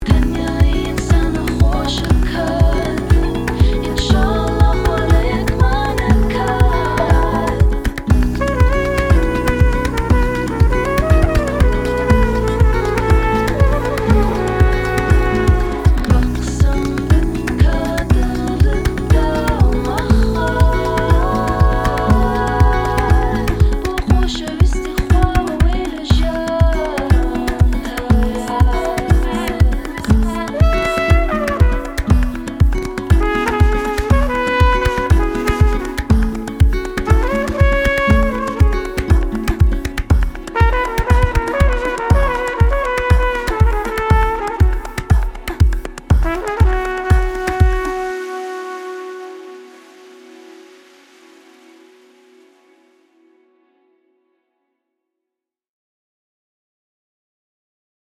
• Folk
• Indie
• Singer/songwriter
Solo koncert med mig og min guitar
Musikalsk blander hun den nordiske melankoli med den kurdiske inderlighed, varme og kraftfuldhed. Med sin guitar og med få effekter skaber hun et magisk, poetisk og sonisk univers, som trylle-binder lytteren ved første sekund.